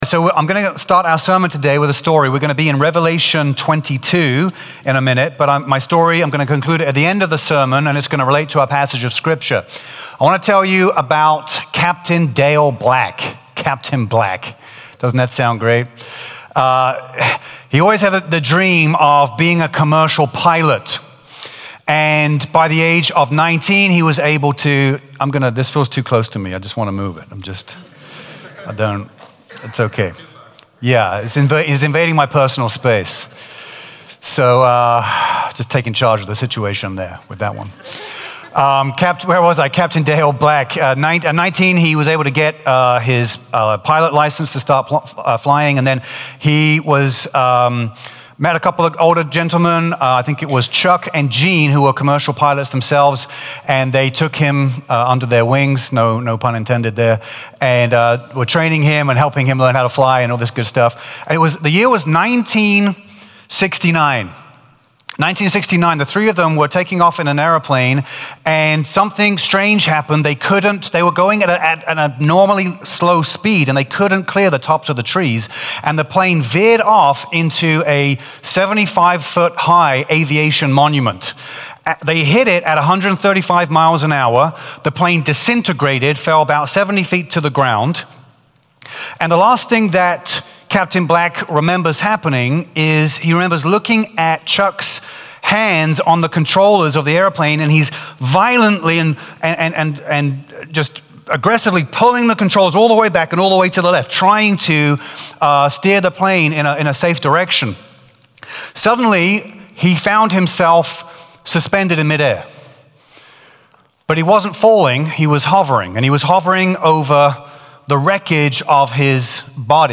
A message from the series "Heaven's Mysteries."